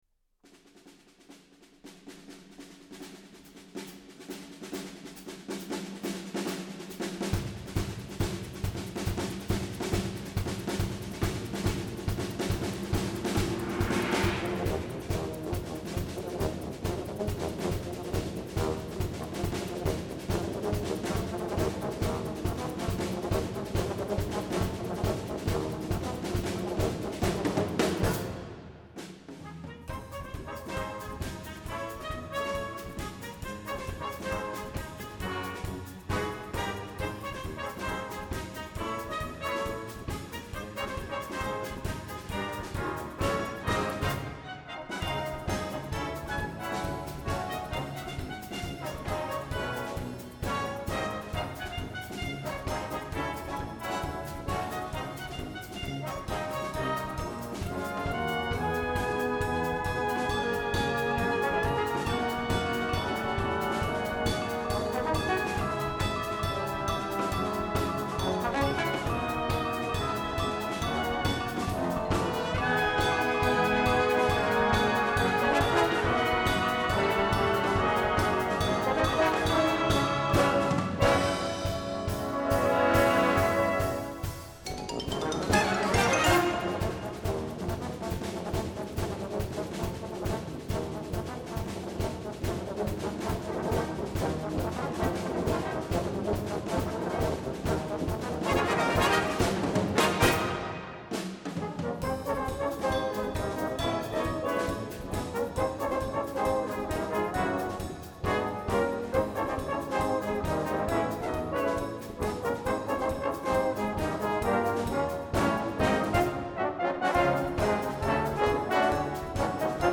Brass Band version
Instrumentation: Brass Band: This is a latin chart.